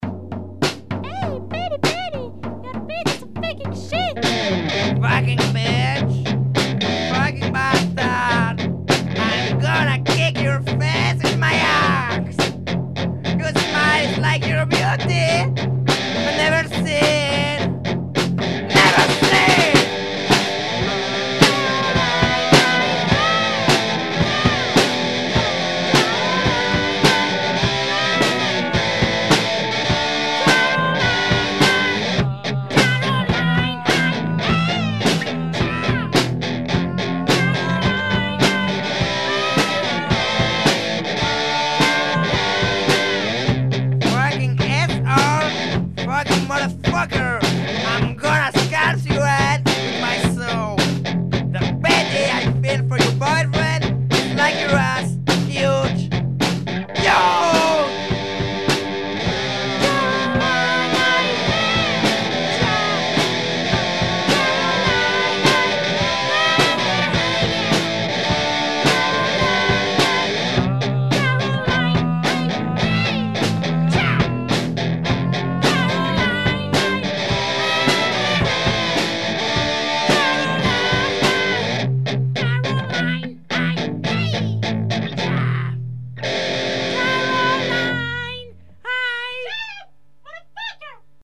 Extreme punk